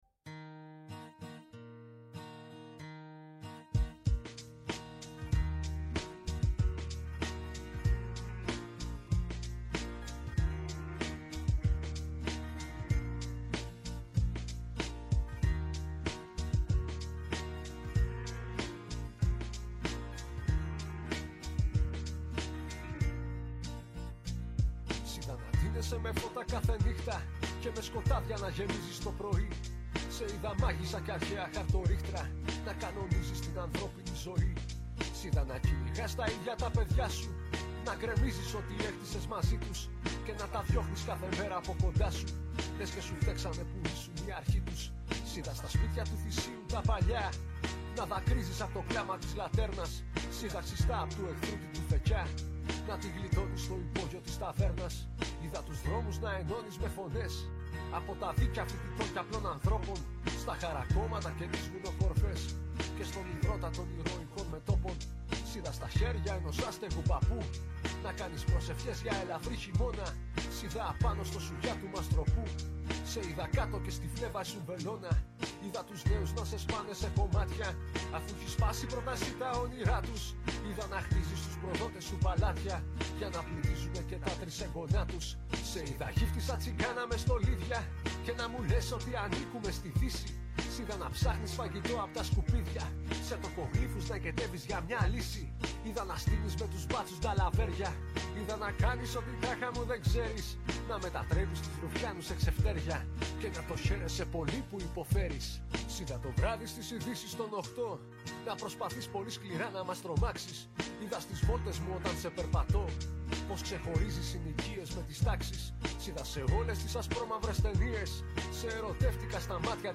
μουσικές της Έβδομης Τέχνης.